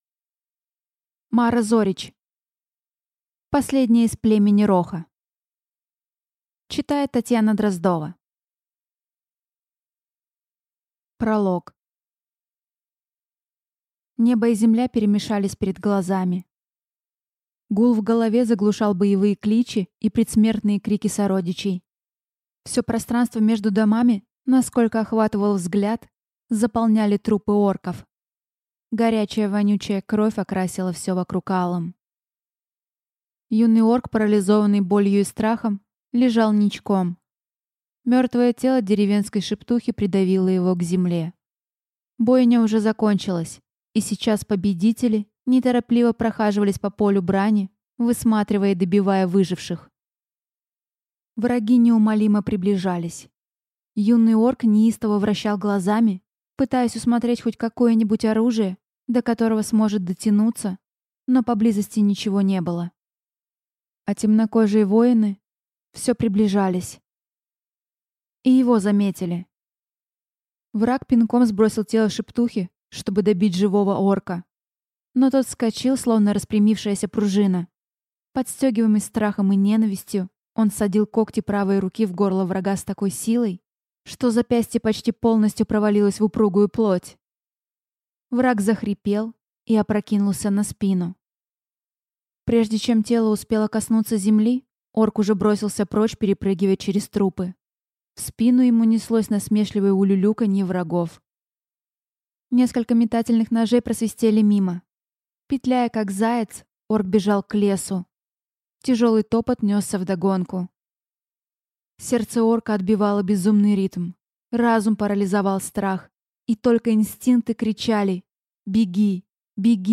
Аудиокнига Последняя из Племени Роха | Библиотека аудиокниг